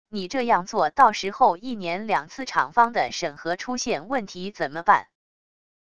你这样做到时候一年两次厂方的审核出现问题怎么办wav音频